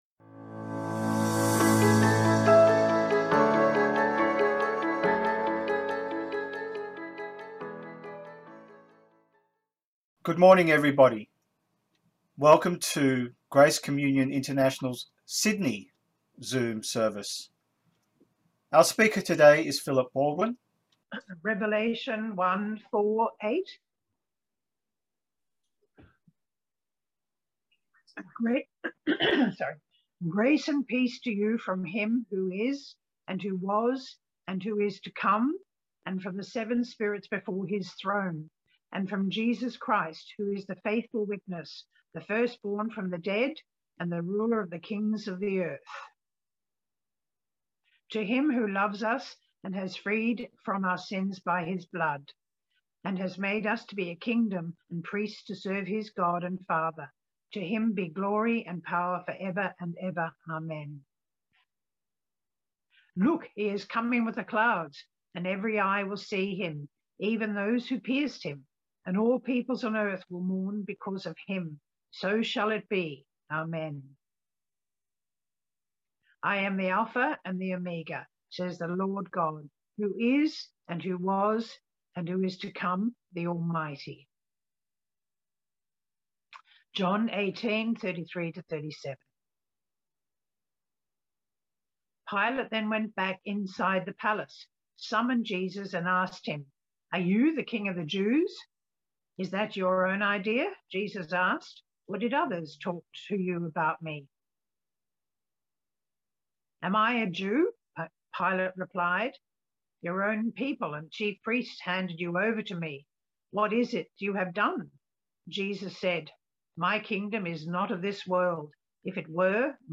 From Series: "Services"
Scripture Reading
Revelation 1:4-8 John 18:33-37 Sermon